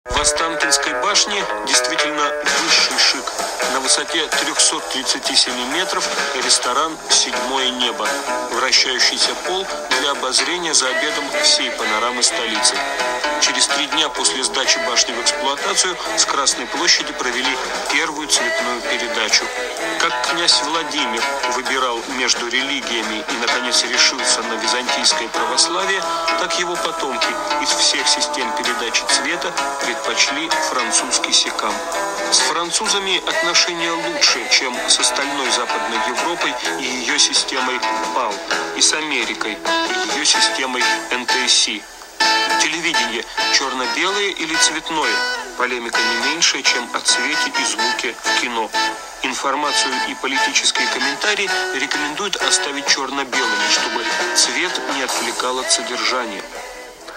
Помогите, пожалуйста, опознать серф-группу